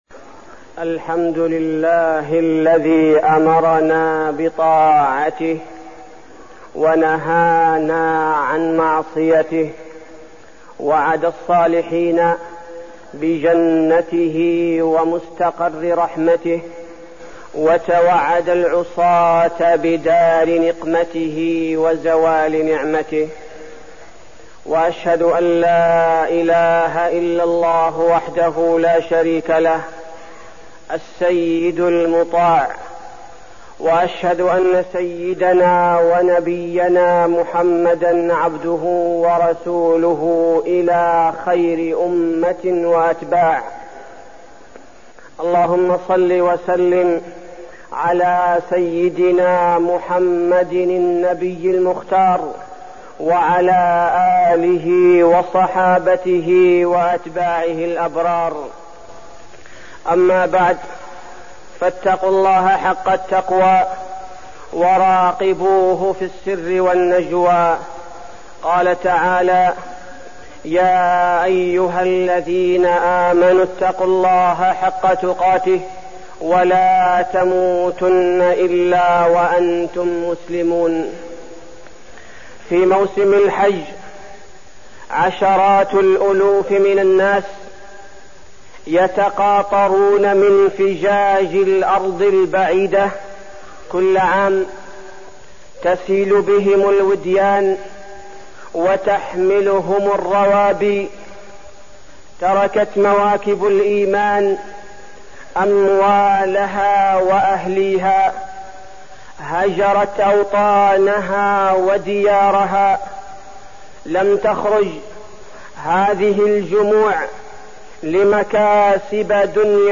تاريخ النشر ٢٢ ذو القعدة ١٤١٨ هـ المكان: المسجد النبوي الشيخ: فضيلة الشيخ عبدالباري الثبيتي فضيلة الشيخ عبدالباري الثبيتي رسالة للحجاج